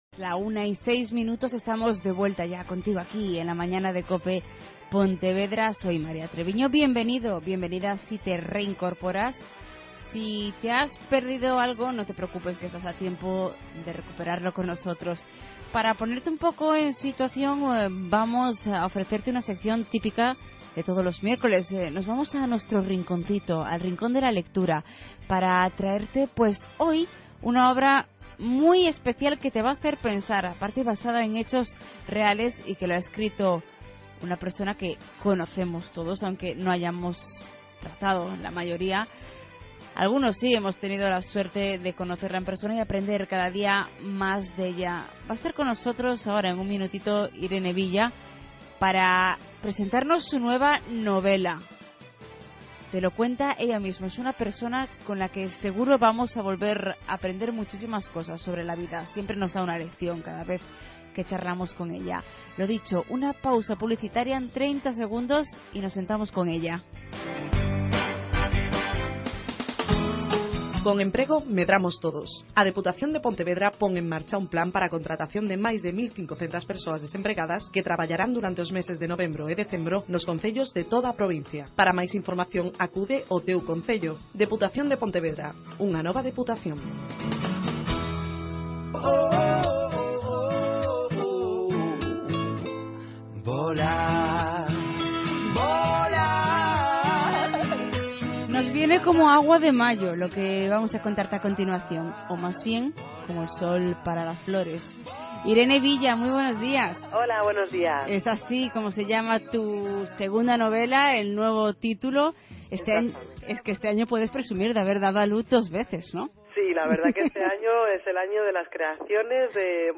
AUDIO: Charla en profundidad con Irene Villa, que como siempre nos muestra su positividad.